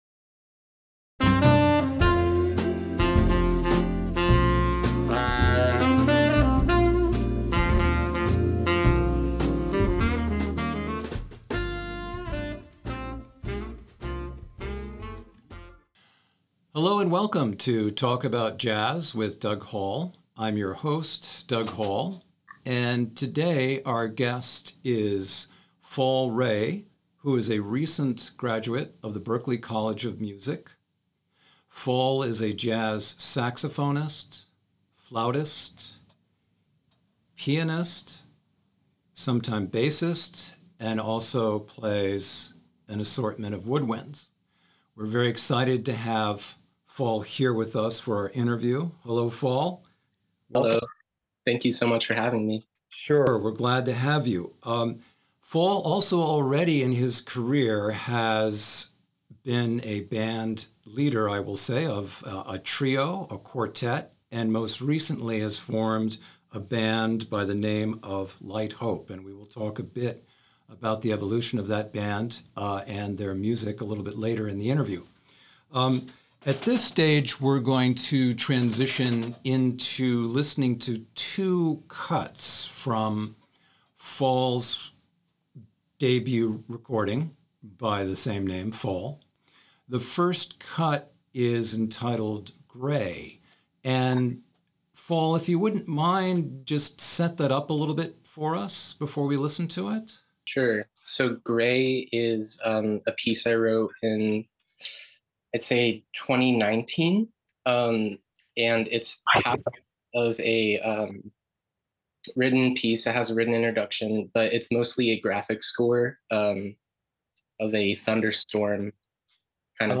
You’ll hear two tracks from the album along with an insightful conversation: